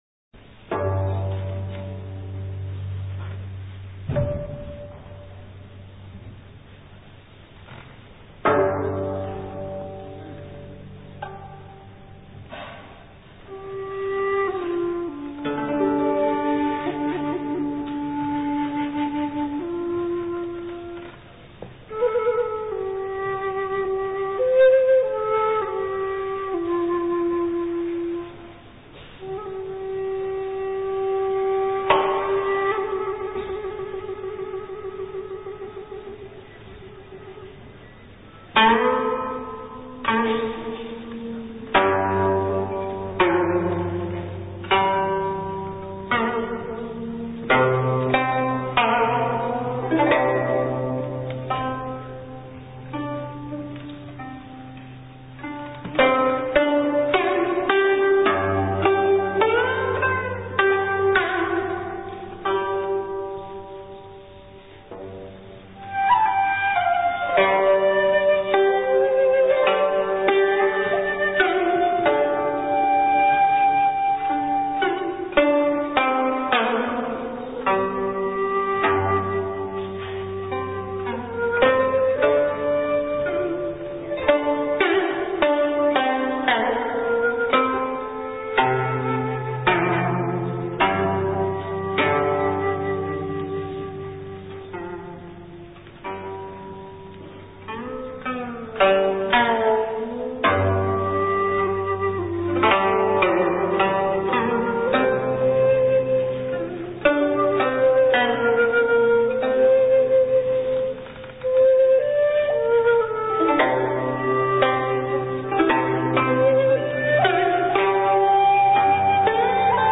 演奏：古筝